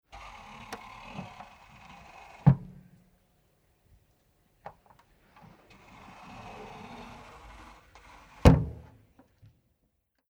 Television Philips Discoverer, Mod. 14GR1220/22B
Move lid
24857_Deckel_bewegen.mp3